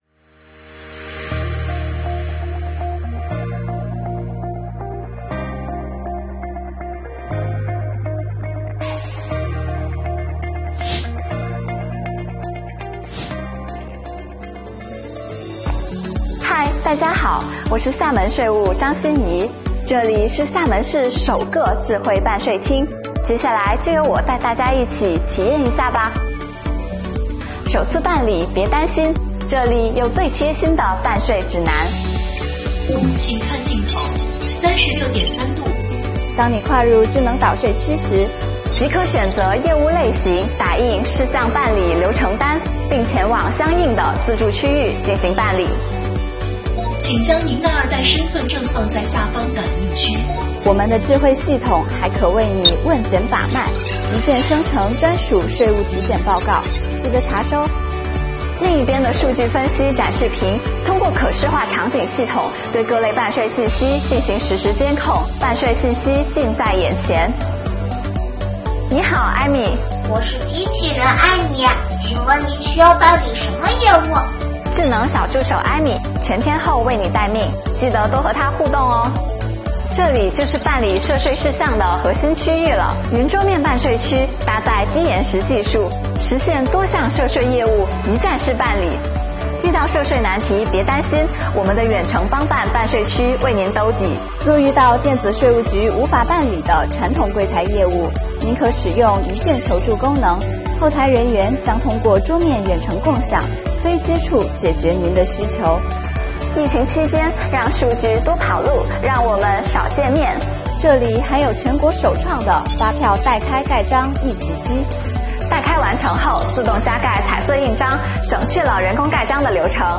跟着我们的税务小姐姐一起开启智慧办税之旅吧~